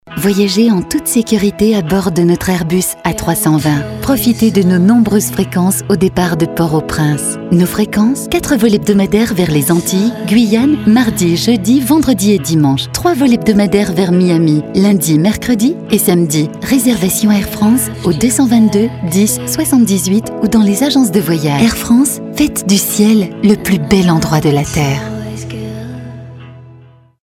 Mairies, Communautés de Communes ou Union des Commerçants, nous avons réalisé pour eux les spots publicitaires qu'ils souhaitaient !